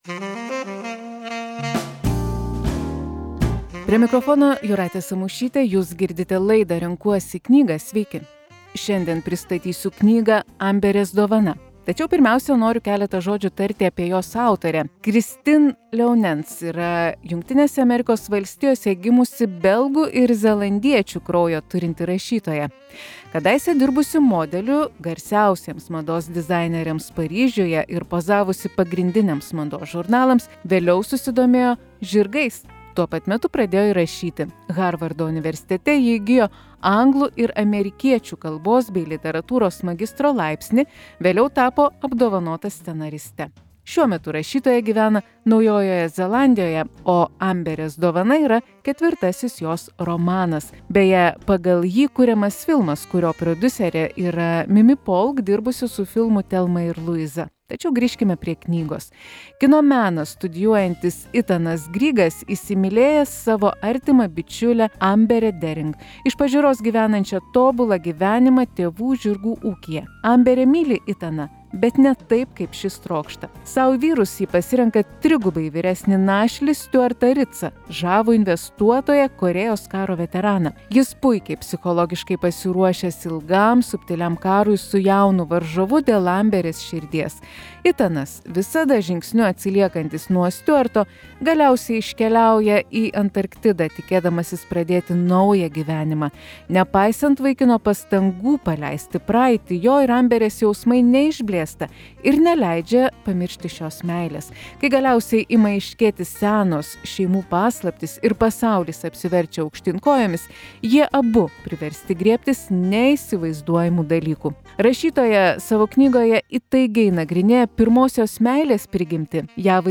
Knygos apžvalga.